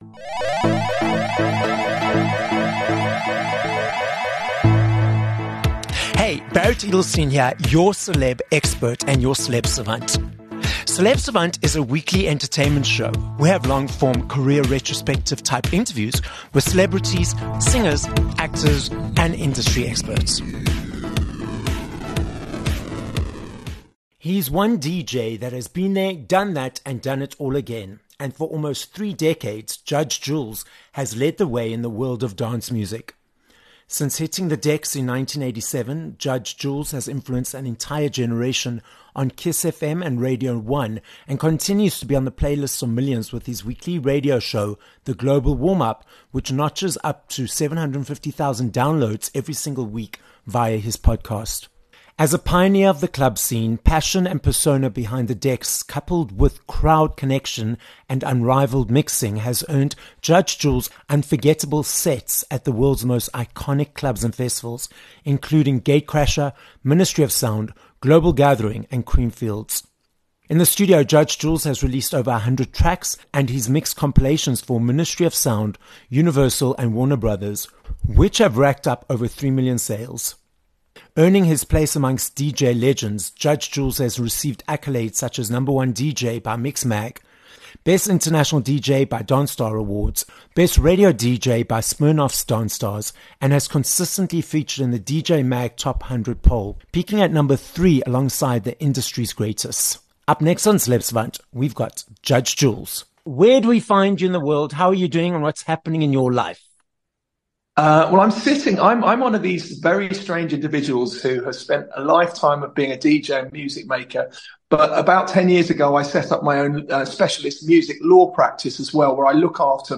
29 Oct Interview with Judge Jules